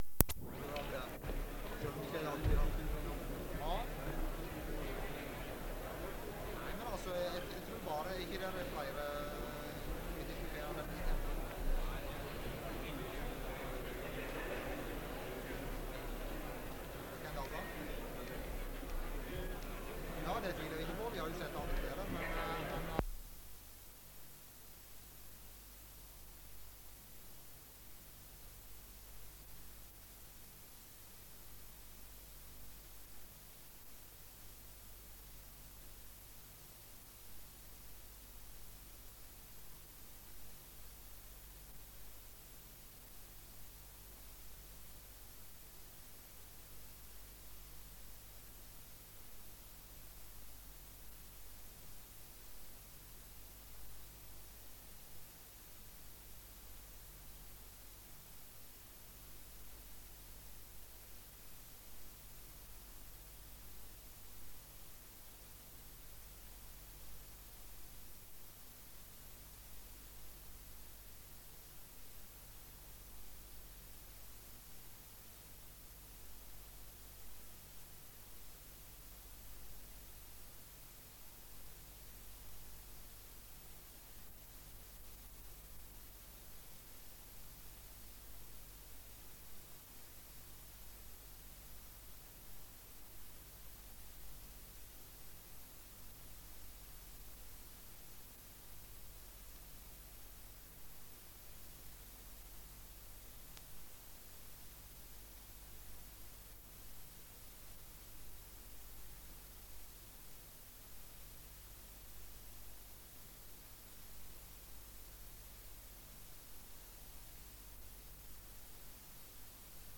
Det Norske Studentersamfund, Generalforsamling, 29.11.1975